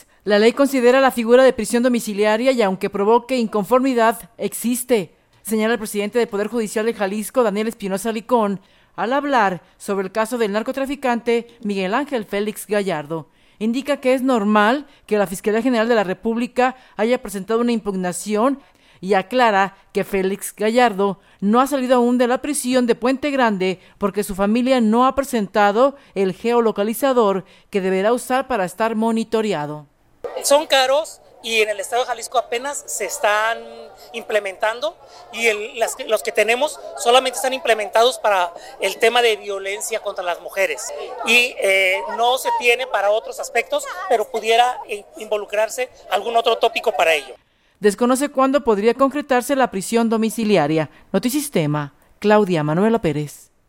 La ley considera la figura de prisión domiciliaria y aunque provoque inconformidad existe, señala el presidente del Poder Judicial en Jalisco, Daniel Espinosa Licón, al hablar sobre el caso del narcotraficante Miguel Angel Félix Gallardo.